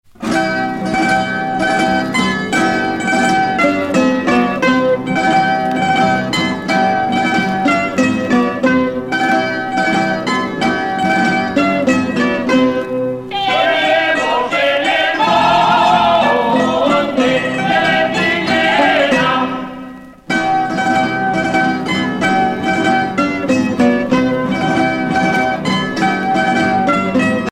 danse : jota (Espagne)
Pièce musicale éditée